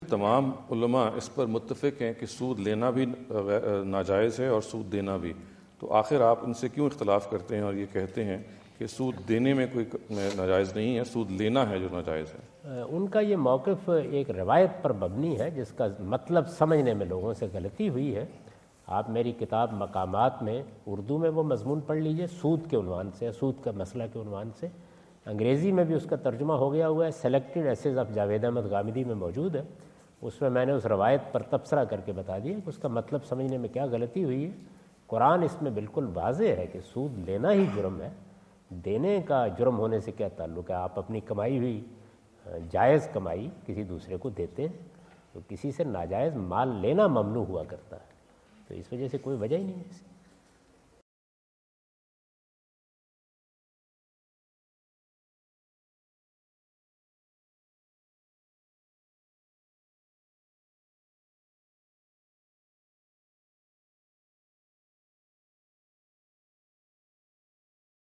Category: English Subtitled / Questions_Answers /
Javed Ahmad Ghamidi answer the question about "Is Paying Interest Prohibited?" asked at The University of Houston, Houston Texas on November 05,2017.